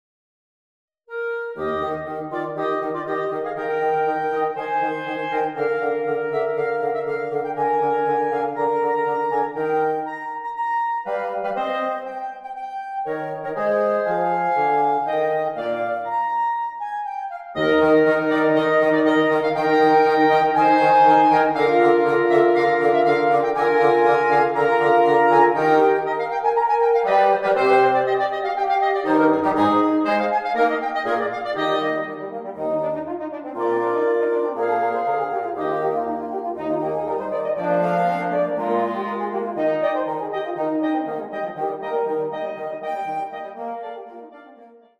2 clarinets, 2 horns, 2 bassoons
(Audio generated by Sibelius/NotePerformer)